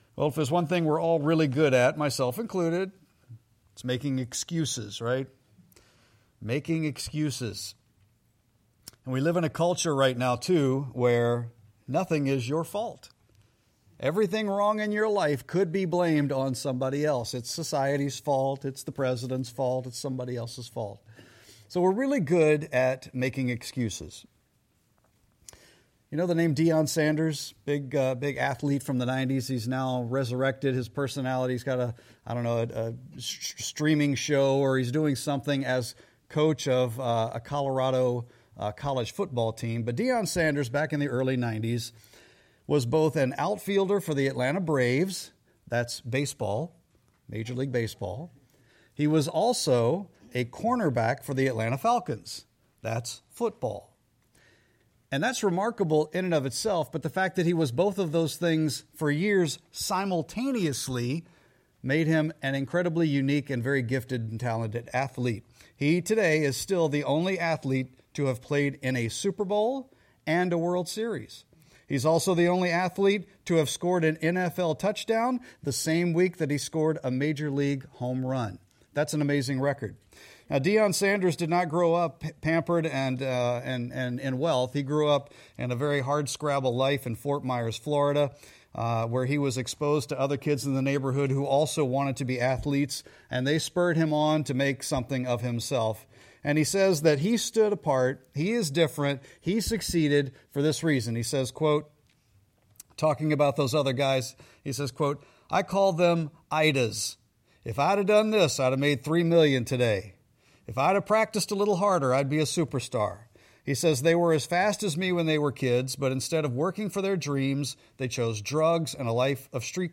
Sermon-2-15-26.mp3